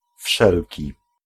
Ääntäminen
IPA : /ˈɛv.ɹi/